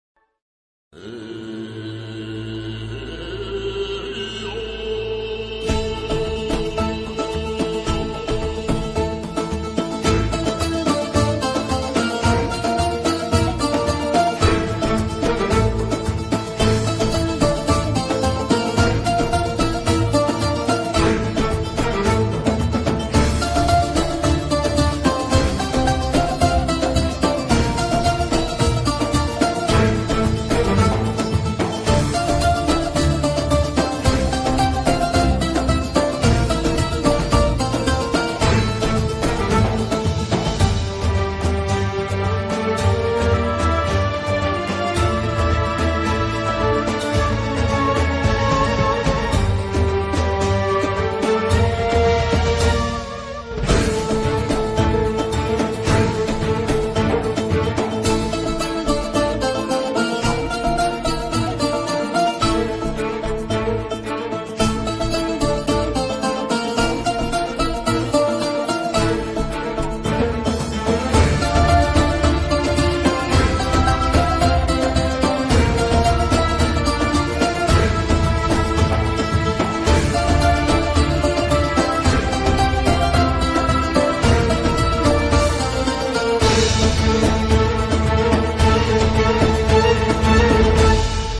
Заставка и музыка в начале серий.